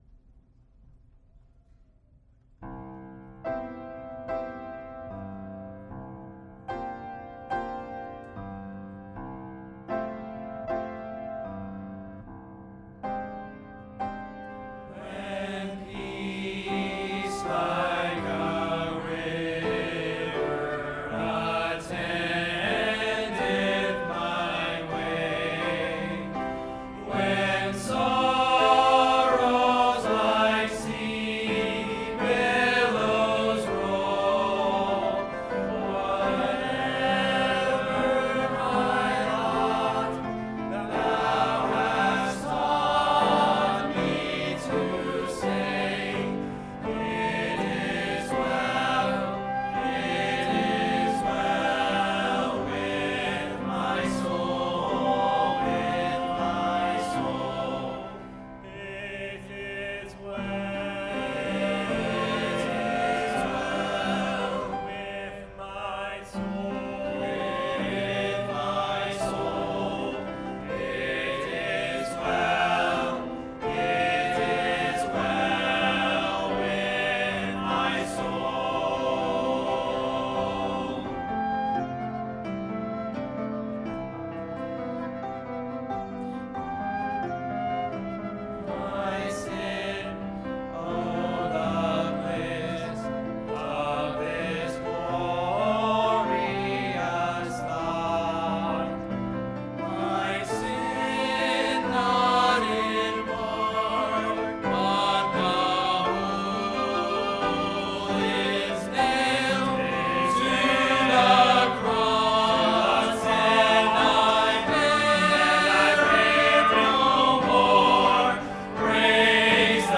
by VBC Men's Ensemble | Verity Baptist Church